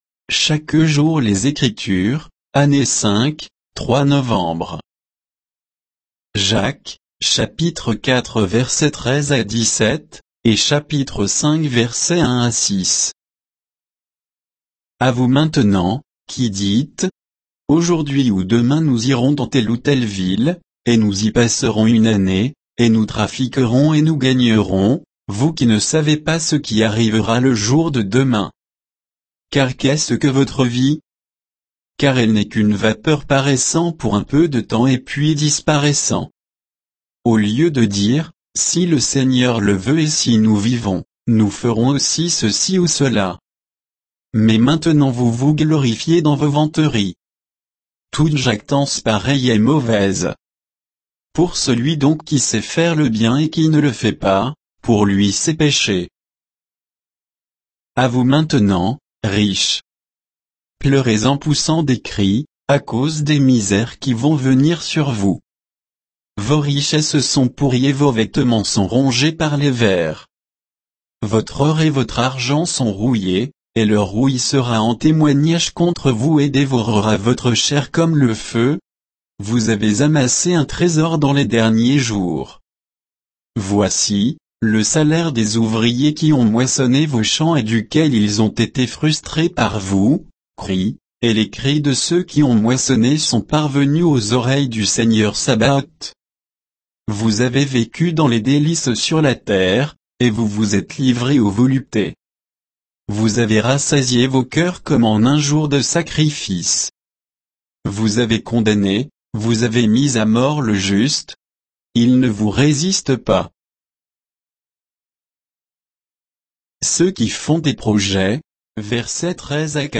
Méditation quoditienne de Chaque jour les Écritures sur Jacques 4